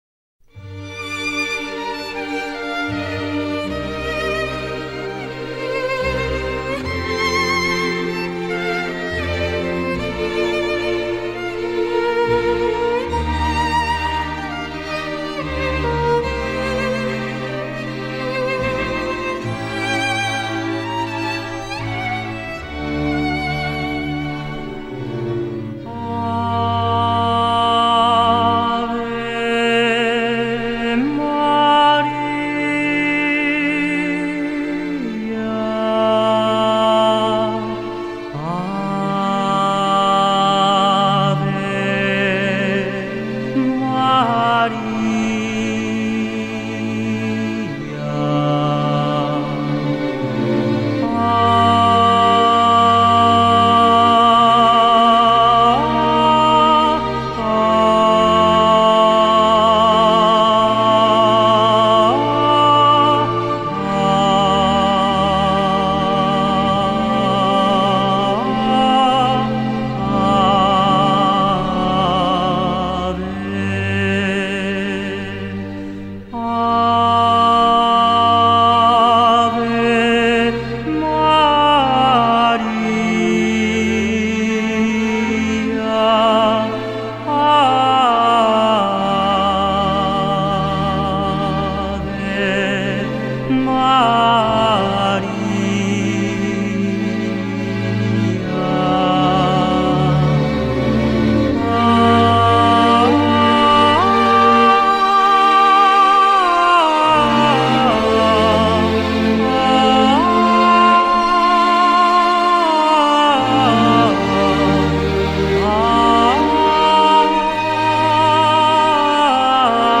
风格流派：Crossover